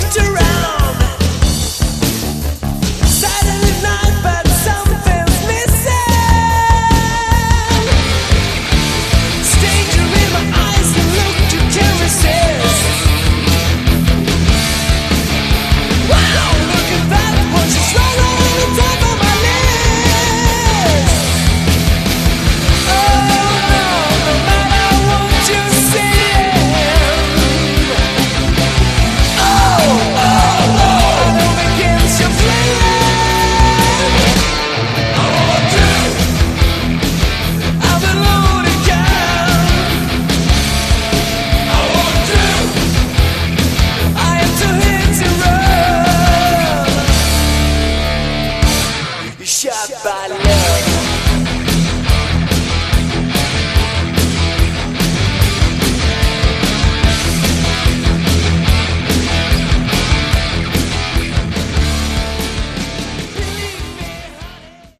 Category: Sleaze/Hard Rock
Guitars
Bass
Drums
demo bonus track